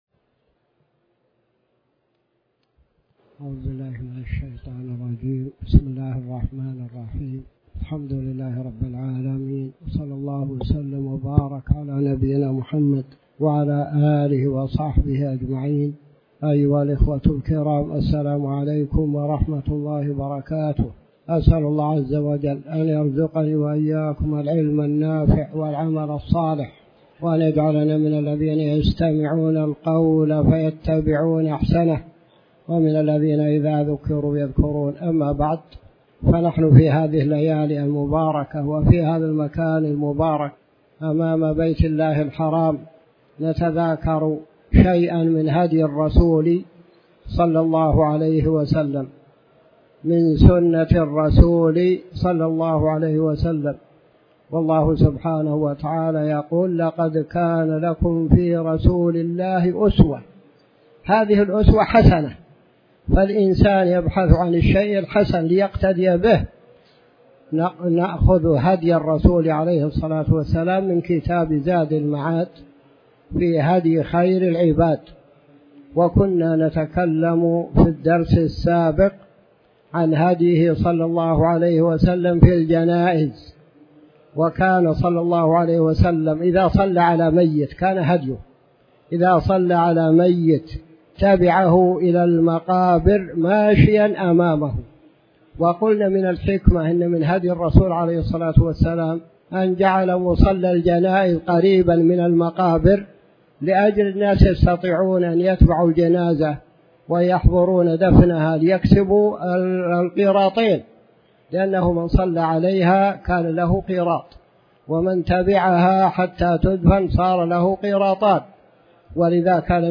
تاريخ النشر ٨ محرم ١٤٤٠ هـ المكان: المسجد الحرام الشيخ